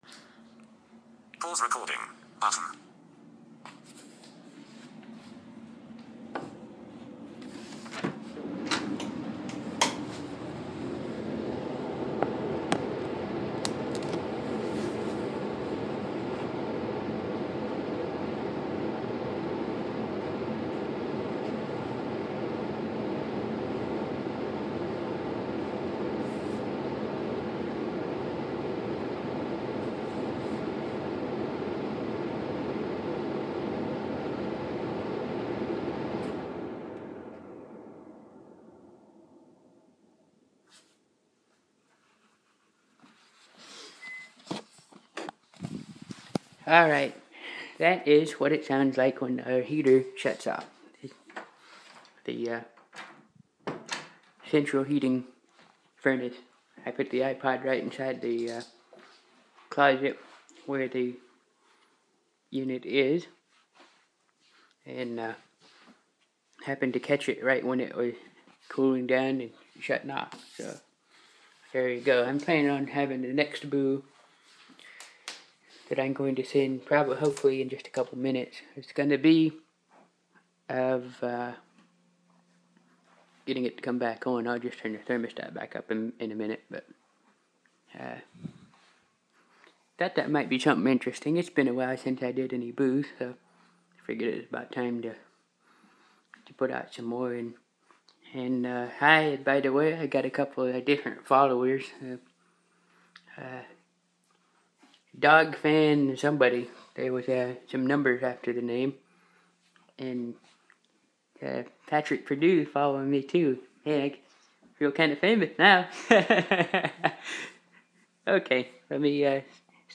This is a recording of our central heating system shutting down